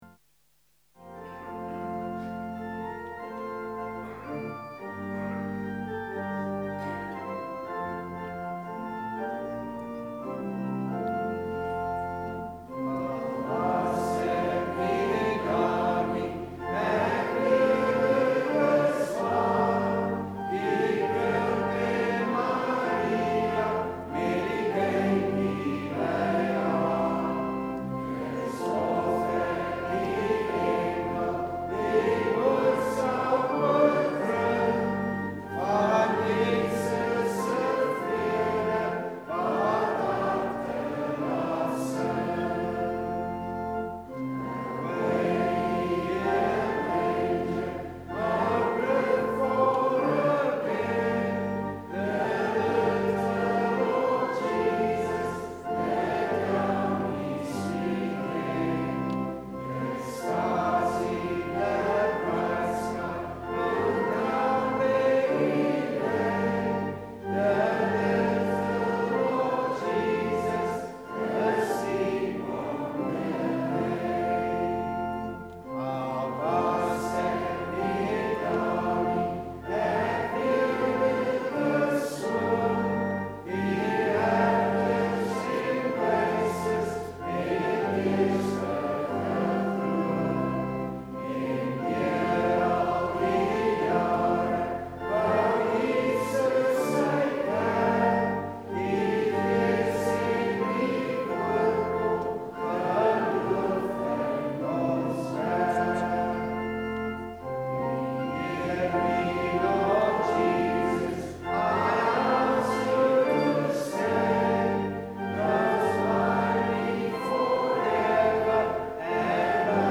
Carol Service (A&E)